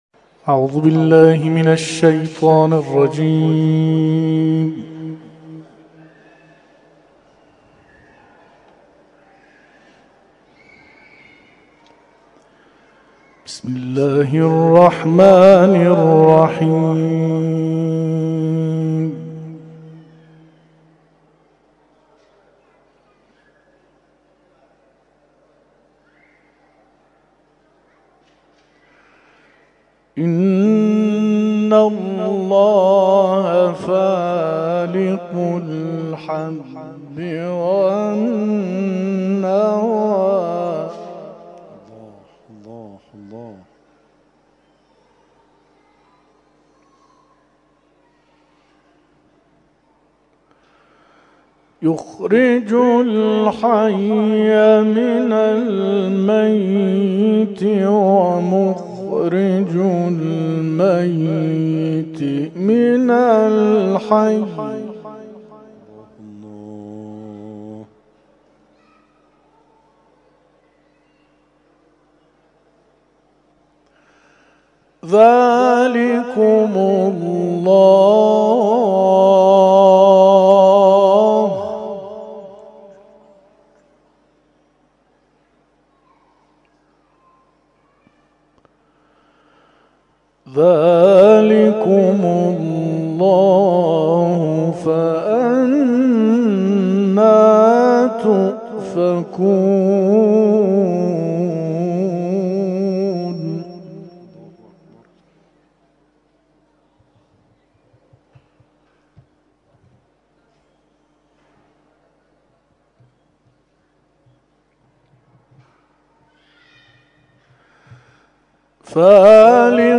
به گزارش خبرگزاری بین‌المللی قرآن(ایکنا)، به همت مرکز امور قرآنی آستان مقدس حضرت عبدالعظیم الحسنی(ع)، دو هزارمین محفل انس با قرآن کریم این آستان مقدس شام‌گاه جمعه، ۲۴ شهریورماه برگزار شد.